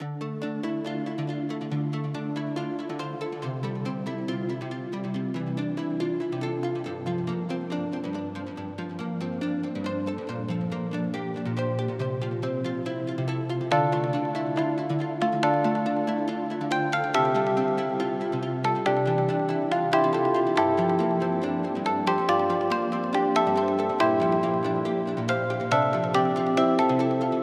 BLACKHEART 140bpm Cmaj.wav